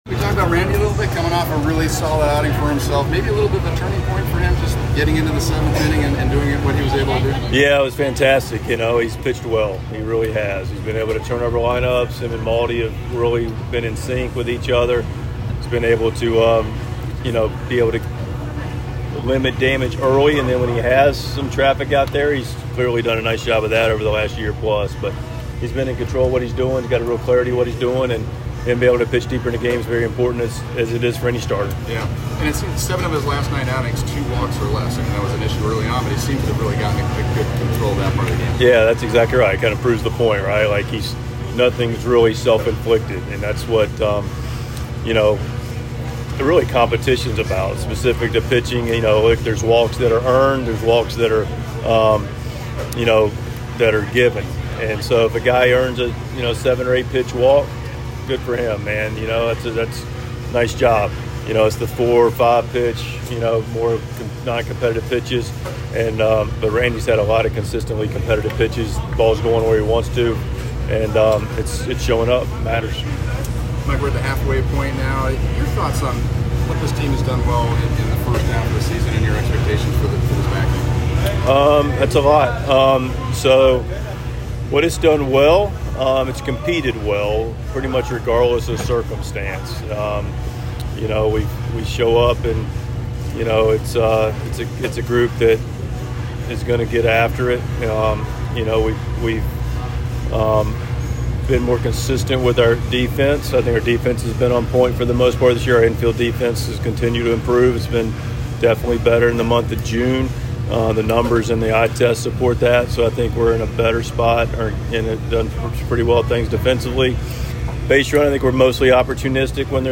Padres manager Mike Shildt's pregame press conference before the team's game against the Cincinnati Reds on Saturday afternoon.